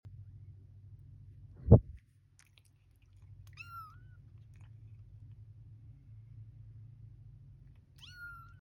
Newborn Black Kitten Meowing And Sound Effects Free Download
Newborn black kitten meowing and